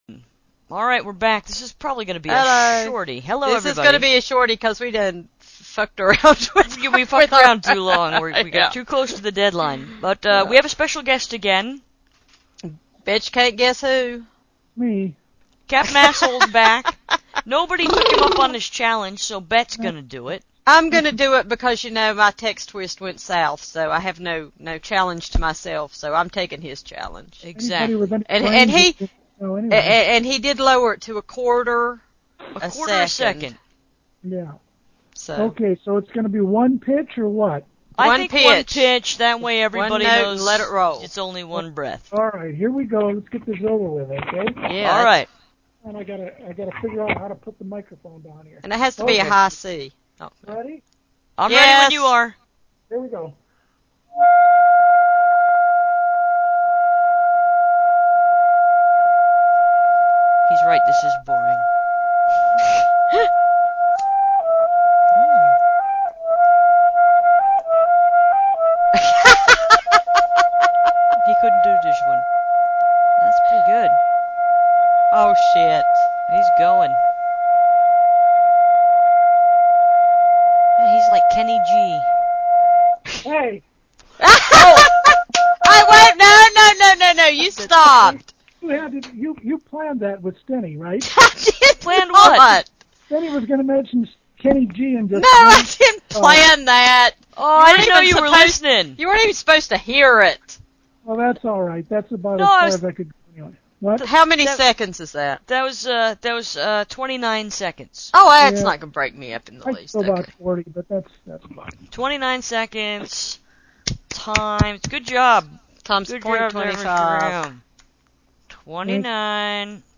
His voice was muted and poor quality.